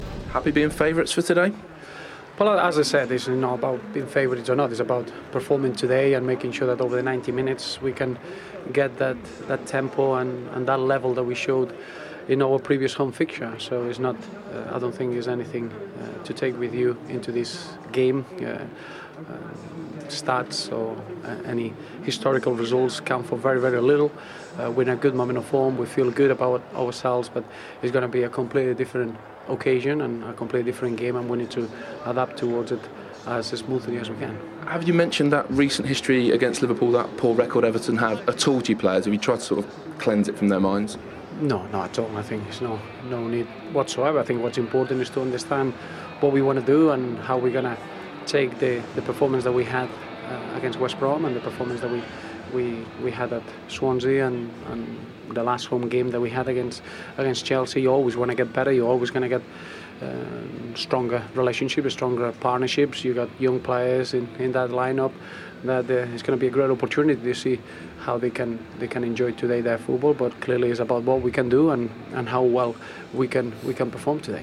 Everton boss Roberto Martinez speaks to Sky Sports ahead of their Super Sunday clash against Liverpool.